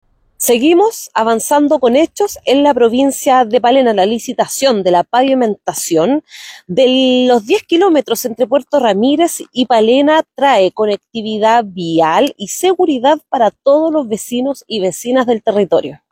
Mismas palabras las de la delegada presidencial provincial, Marisol Mora.